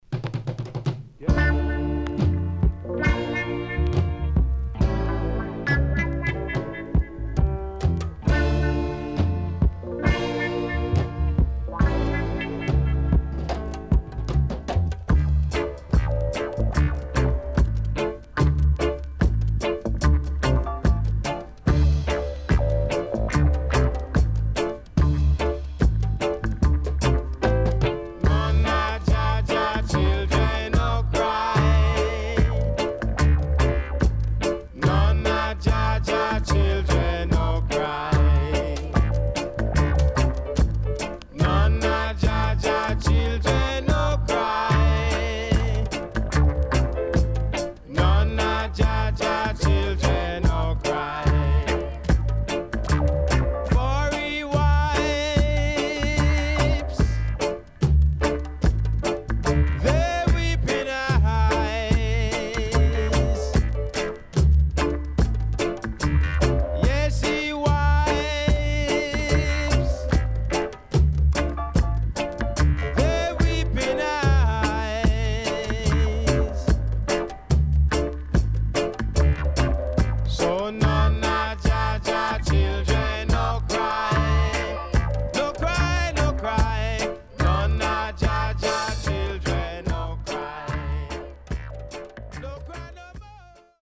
SIDE A:所々ノイズ入ります。